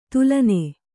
♪ tulae